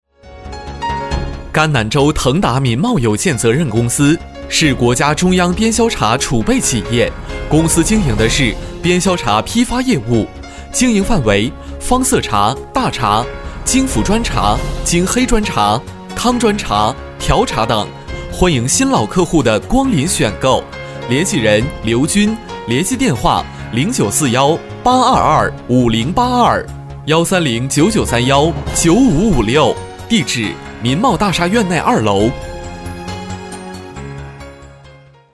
【广告】州腾达民贸男91-轻快.mp3